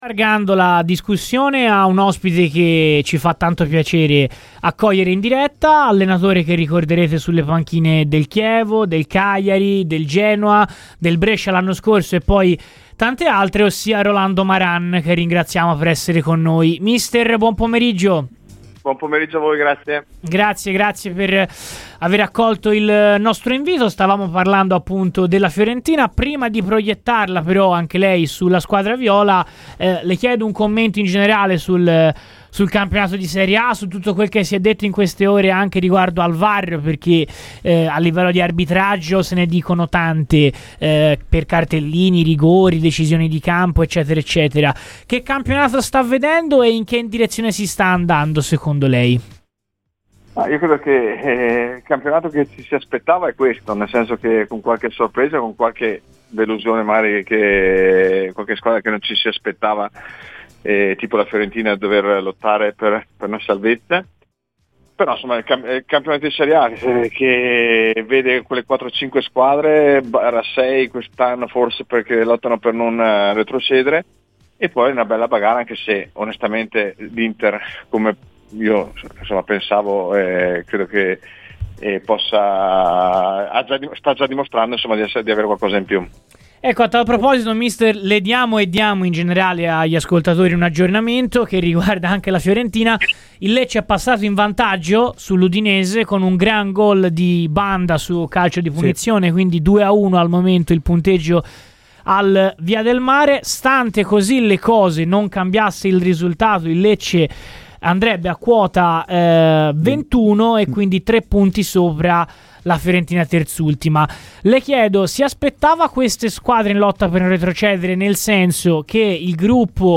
L'allenatore Rolando Maran è intervenuto a Radio FirenzeViola durante il contenitore pomeridiano "Viola Weekend" per parlare della Fiorentina e di campionato: "Ci si aspettava questo campionato, a parte qualche sorpresa come la Fiorentina, ma ci sono bagarre in coda e davanti anche se l'Inter ha una marcia in più per lo scudetto".